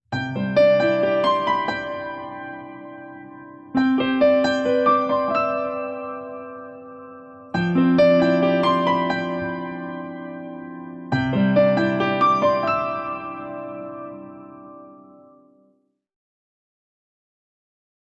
фортепиано